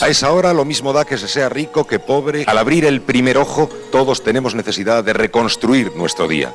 Transmissió, des de l'Hipódromo de la Zarzuela de Madrid, de la Fiesta de la Cadena SER amb motiu de l'estrena de la nova programació.